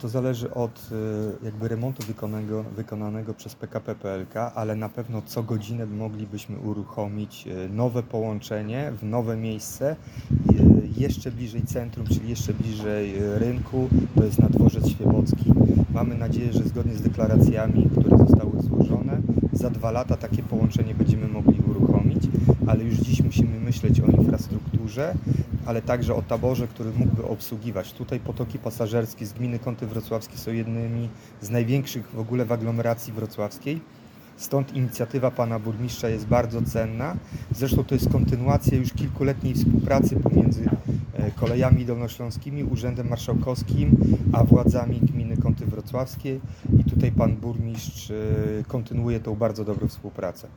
Na zorganizowanym dziś na dworcu kolejowym w Smolcu briefingu, burmistrz Miasta i Gminy Kąty Wrocławskie – Julian Żygadło mówił o rozwoju współpracy pomiędzy gminą a Kolejami Dolnośląskimi.